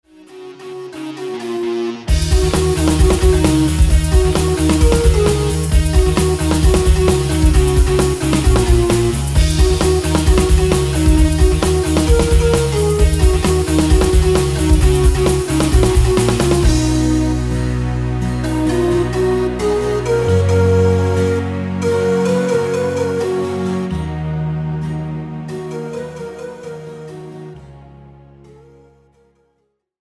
Žánr: Pop
BPM: 132
Key: D#m
MP3 ukázka s ML